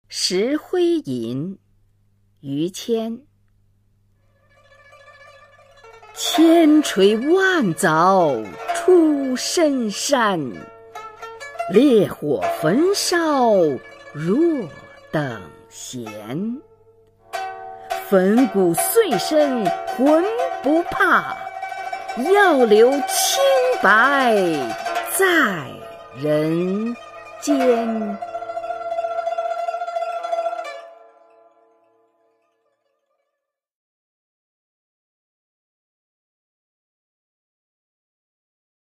[明代诗词诵读]于谦-石灰吟(女) 朗诵